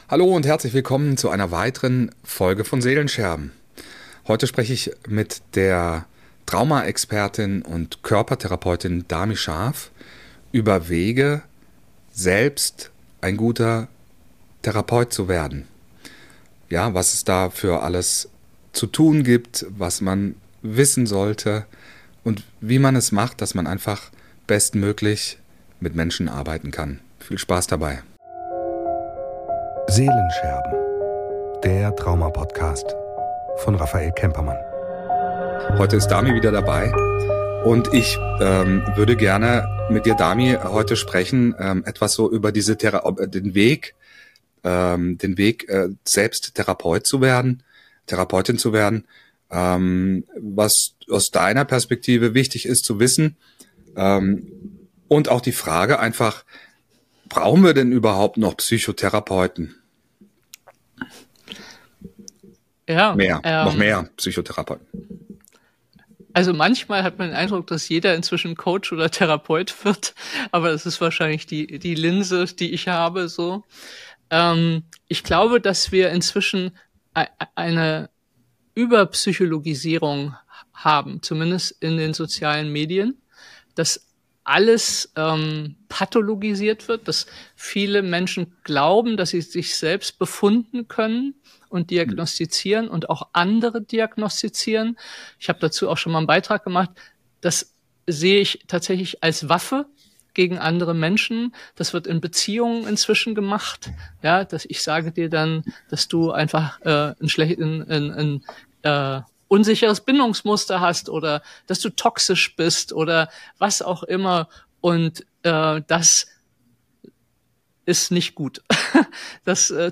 im Gespräch: Trauma – der verborgene Schmerz in uns